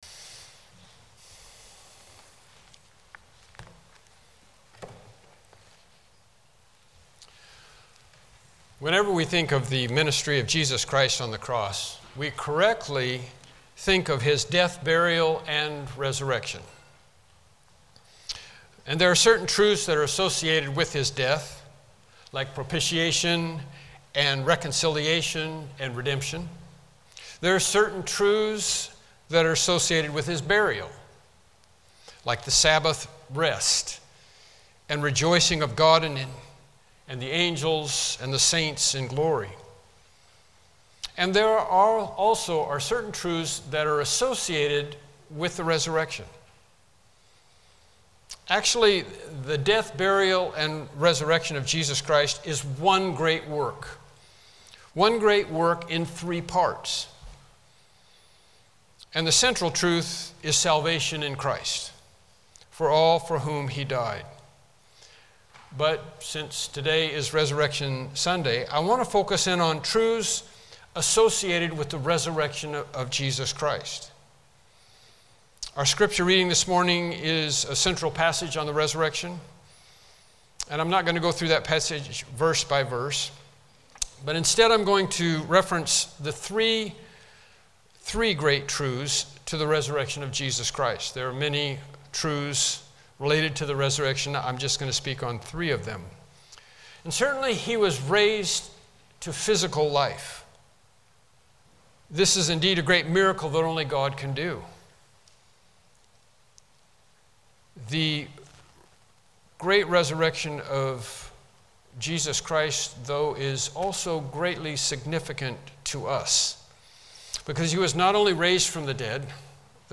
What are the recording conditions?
Passage: 1 Corinthians 15:1-24 Service Type: Morning Worship Service